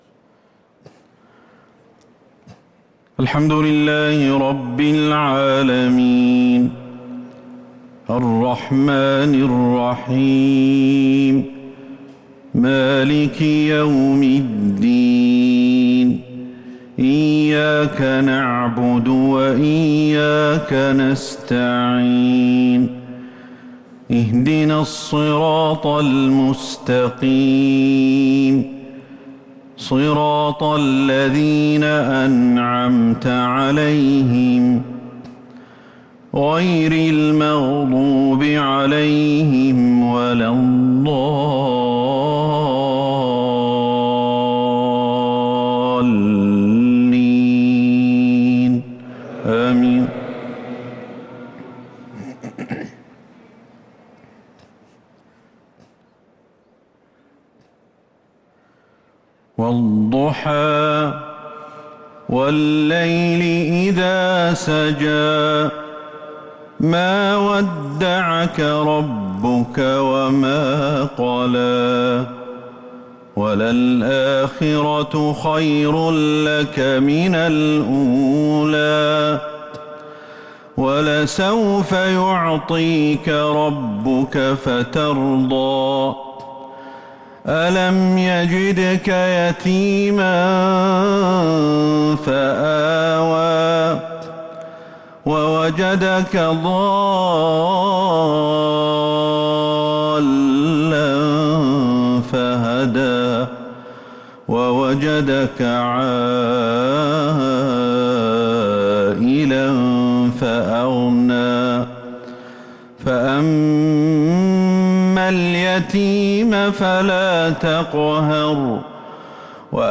صلاة العشاء للقارئ أحمد الحذيفي 9 رمضان 1443 هـ
تِلَاوَات الْحَرَمَيْن .